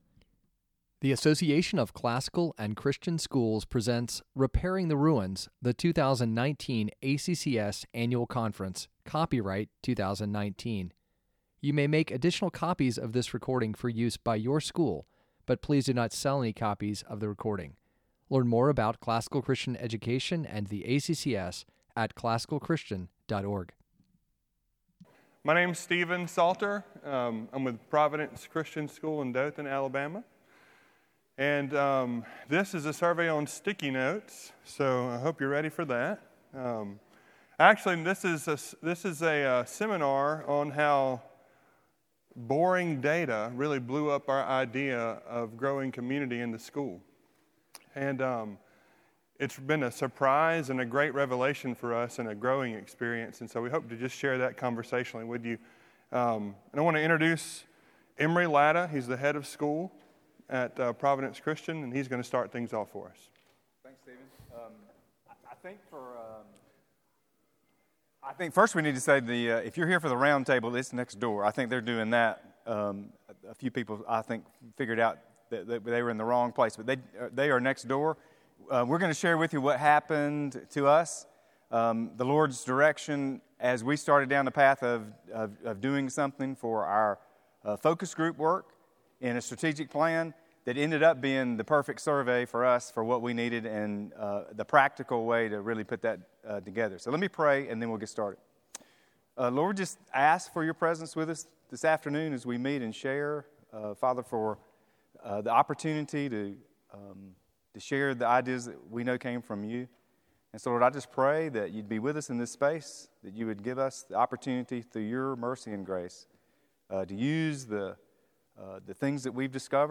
2019 Leaders Day Talk | 47:52 | Fundraising & Development, Leadership & Strategic